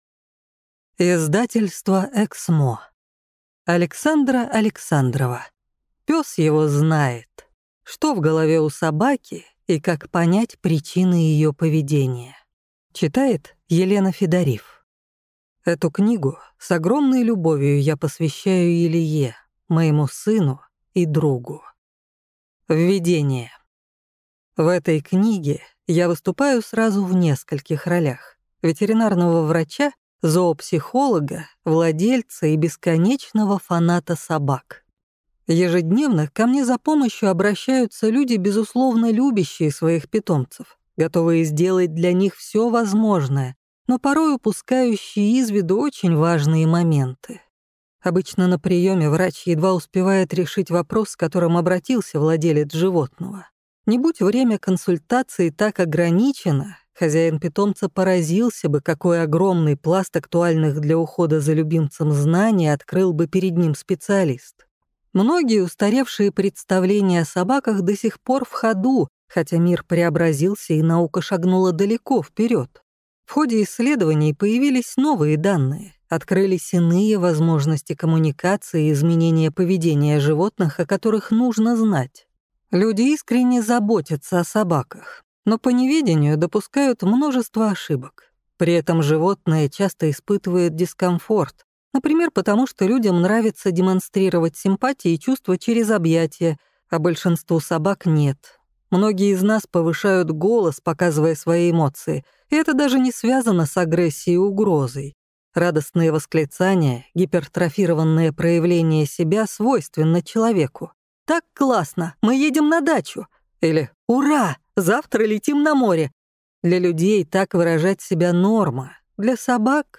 Аудиокнига Пес его знает! Что в голове у собаки и как понять причины ее поведения | Библиотека аудиокниг